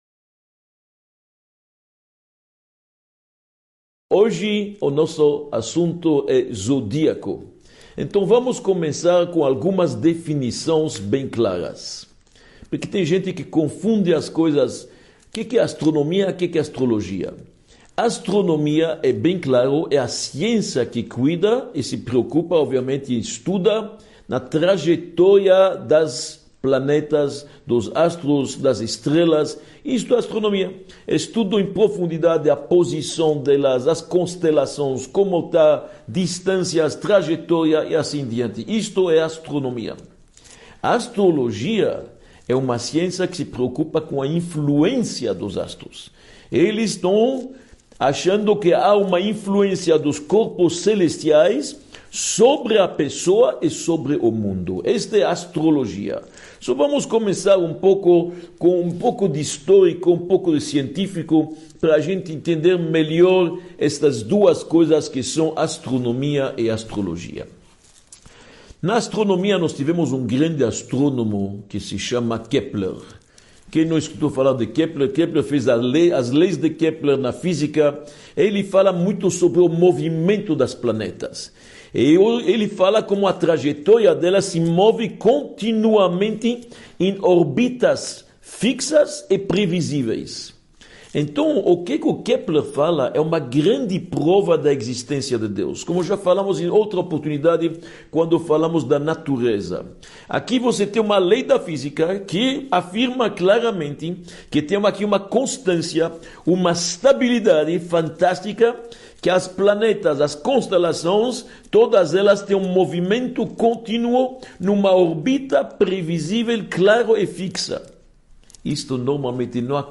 06 – A influência dos corpos celestes – Horóscopo | Os Mistérios do Universo – Aula 06 | Manual Judaico